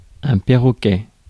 Ääntäminen
Synonyymit poisson-perroquet méditerranéen Ääntäminen France: IPA: [pɛ.ʁɔ.kɛ] Tuntematon aksentti: IPA: /pe.ʁɔ.kɛ/ Haettu sana löytyi näillä lähdekielillä: ranska Käännös Ääninäyte Substantiivit 1. parrot US 2. popinjay 3.